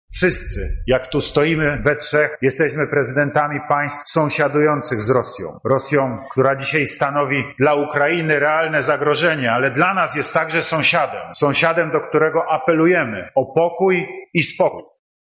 Jest to czas wielkiego testu dla europejskiej solidarności, ale także dla jedności UE i NATO – mówi prezydent RP Andrzej Duda: